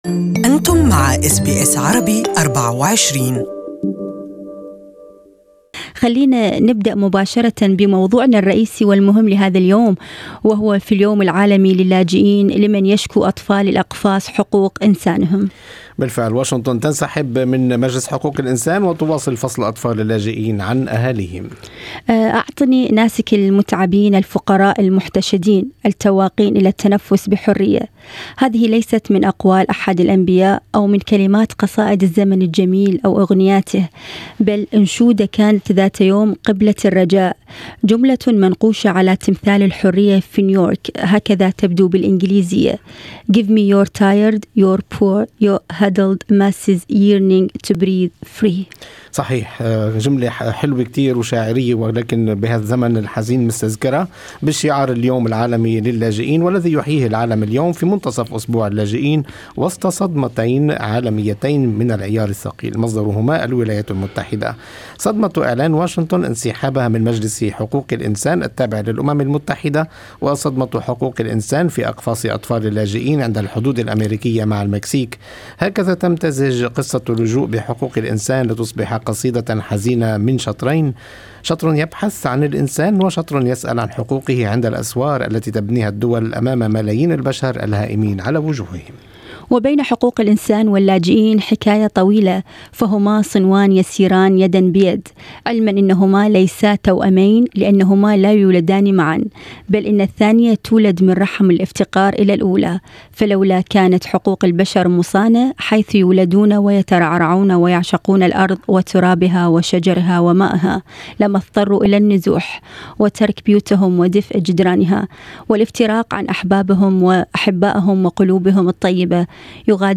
On World Refugee Day, the USA has declared that it will withdraw from the UN Human Rights Council. More in this interview.